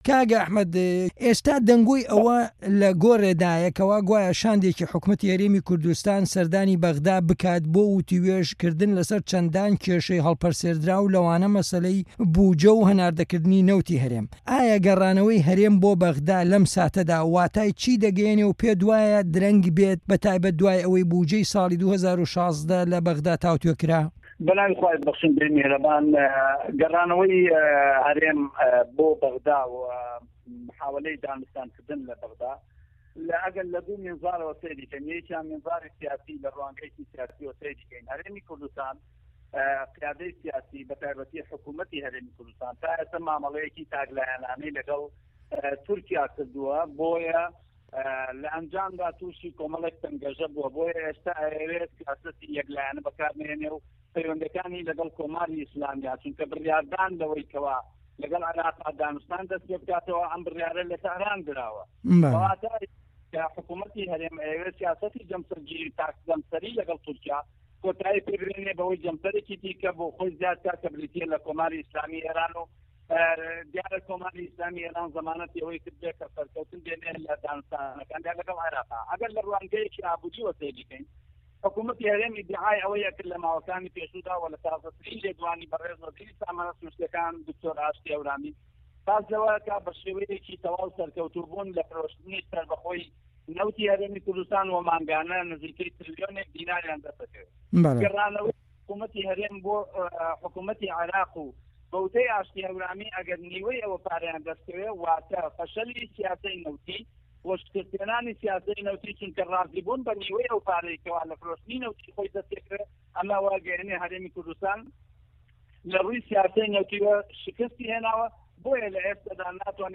وتوێژ لەگەڵ ئەحمەدی حاجی رەشید